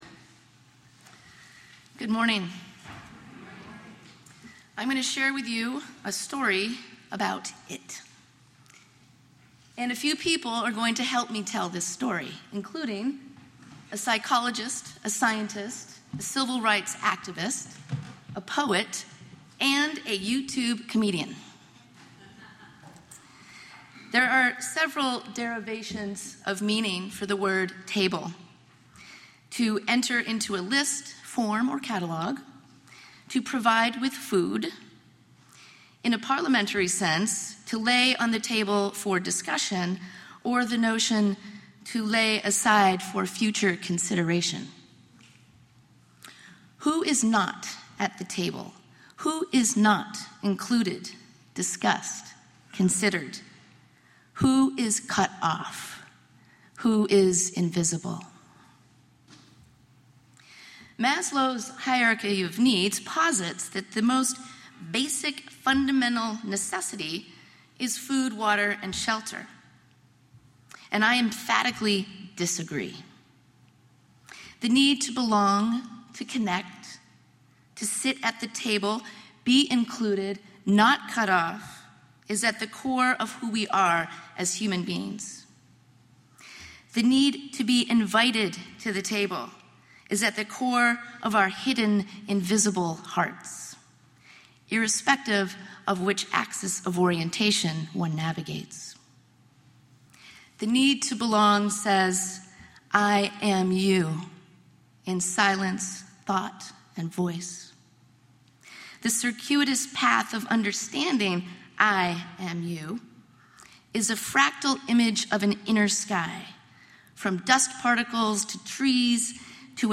With a powerful sermon of reflection, she focuses on some of the more challenging aspects of LGBTQ life experience.